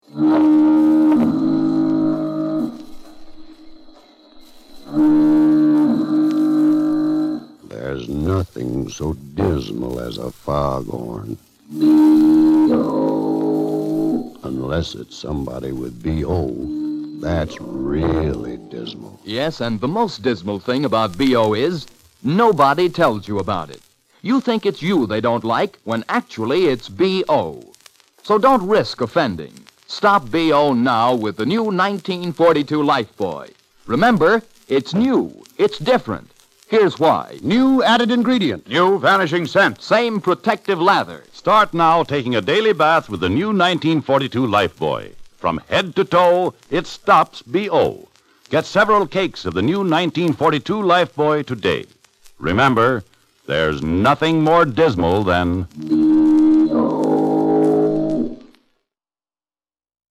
NOW...for the vintage stuff!!!! this material is taken from my private collection of 1940's 16 inch radio transcriptions! these records were what those golden age of radio shows were usually recorded on, as this was before tape!!!! these were HUGE!!! 4 inches wider than a LP, but could only hold about 15 mins a side!!!